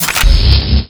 range_plasma.wav